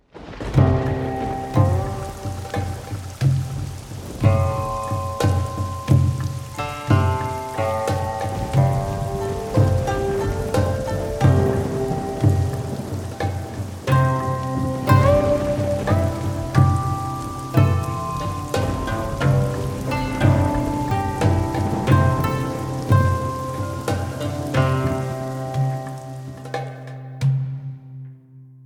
weather_alarm_thunderstorm2.ogg